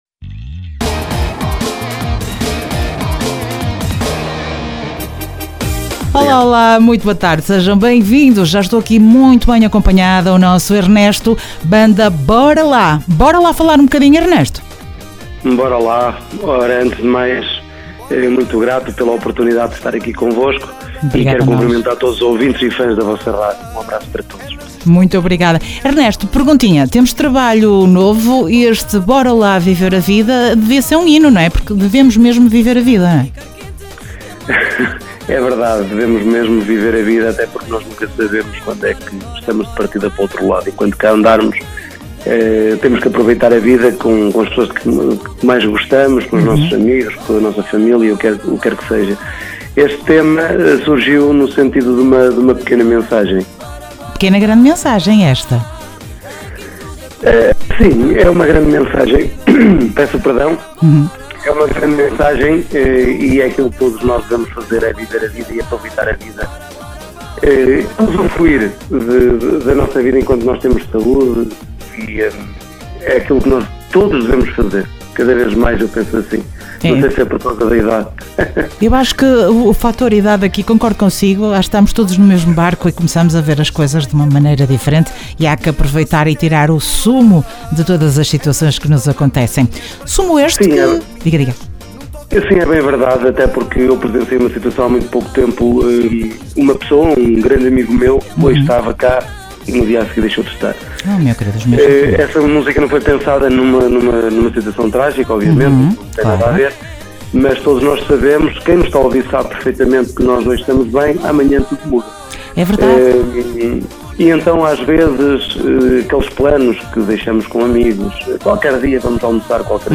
Entrevista Grupo Musica Bora Lá dia 01 de Setembro.